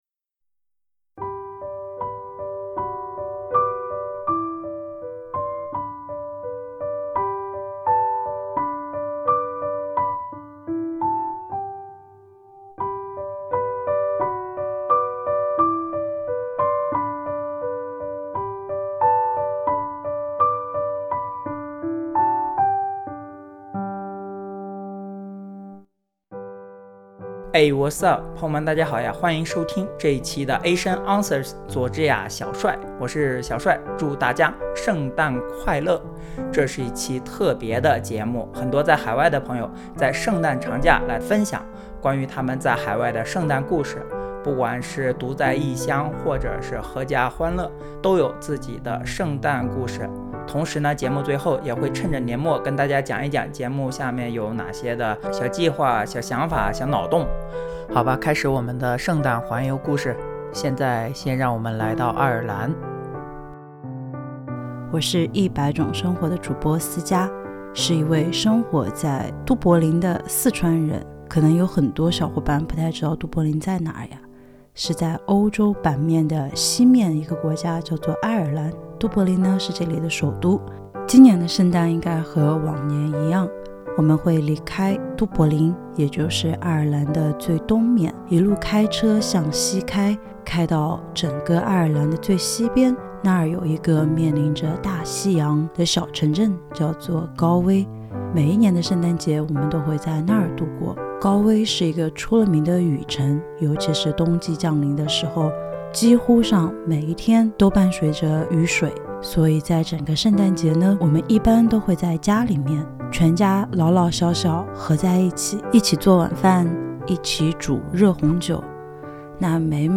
背景音乐 Angels We Have Heard on High Joy to the World We Wish You A Merry Christmas O Come O Come Emmanuel Sight Night